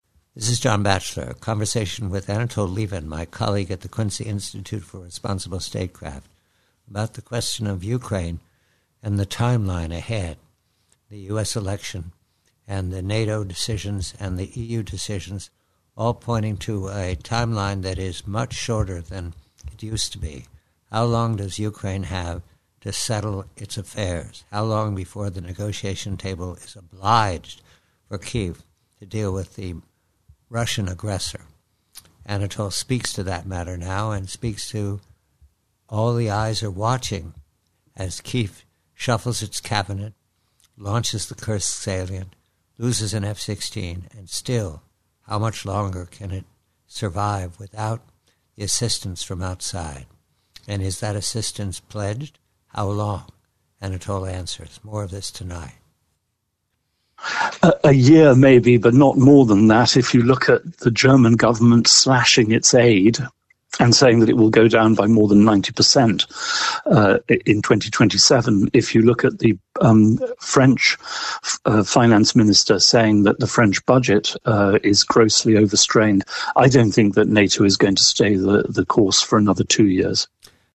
PREVIEW: UKRAINE: TIMELINE: Conversation with colleague Anatol Lieven of the Quincy Institute re how long Ukraine can resist both Russian aggression and the EU and NATO voices urging ceasefire and negotiation.